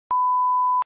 Play, download and share beeeeep original sound button!!!!
beeeeep.mp3